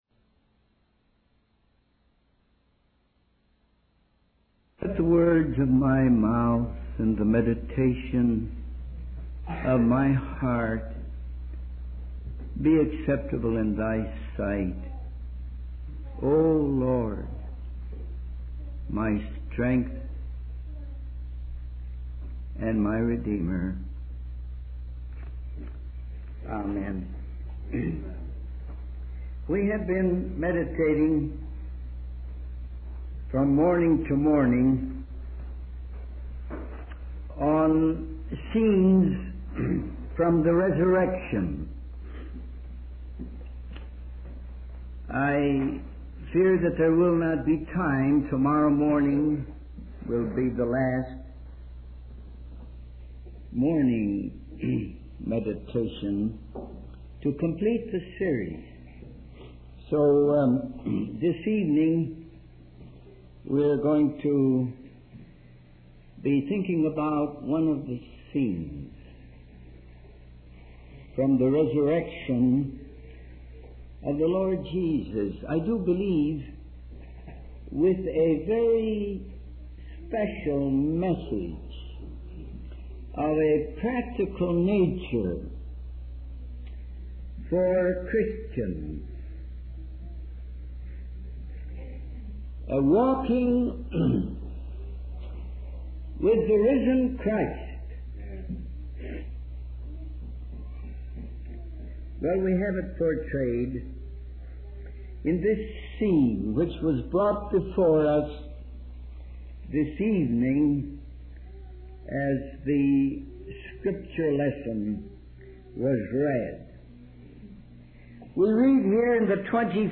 In this sermon, the speaker shares a personal experience he had with a young man who confessed his struggles with living a Christian life.